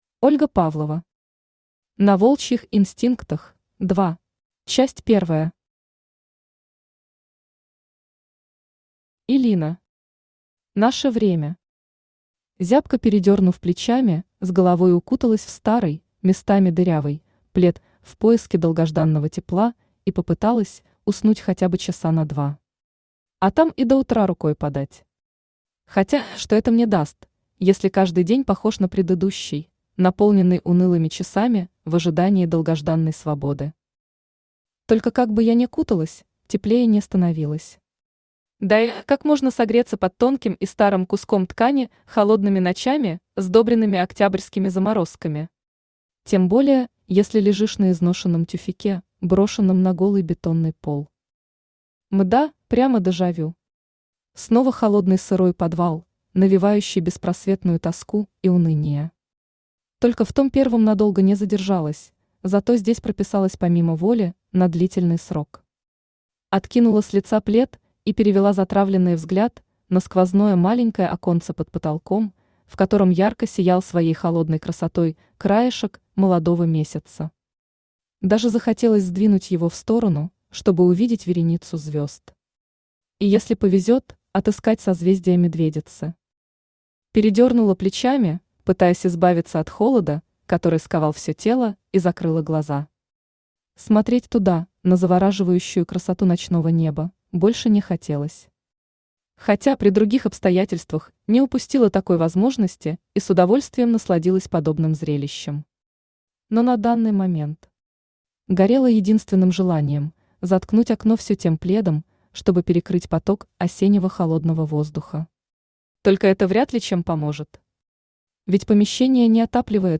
Аудиокнига На волчьих инстинктах – 2 | Библиотека аудиокниг
Aудиокнига На волчьих инстинктах – 2 Автор Ольга Анатольевна Павлова Читает аудиокнигу Авточтец ЛитРес.